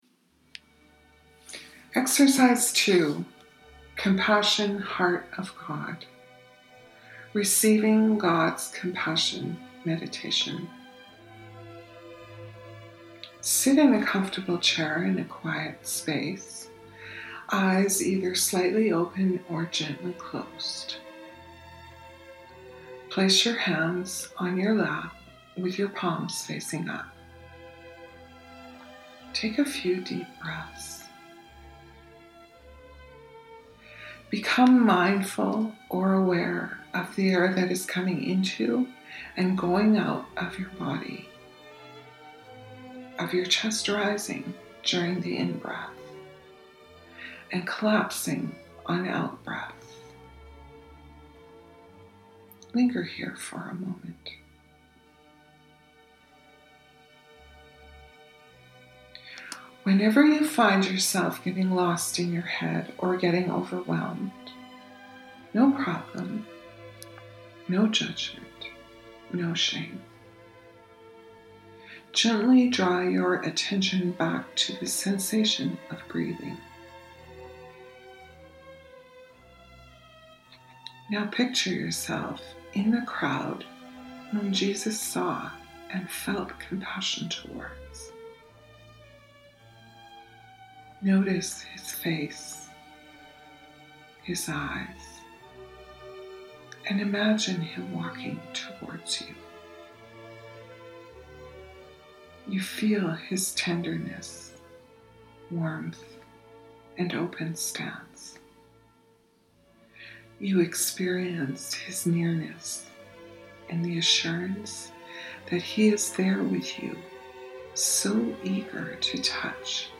The meditation below is an attempt to open our heart and mind to God’s generous gift of compassion. This active yet quiet form of prayer prepares us to be indwelt by the Spirit of God so that we can have the mind of Christ infusing all that we are and all that we do.
Gods-Compassion-Meditation.mp3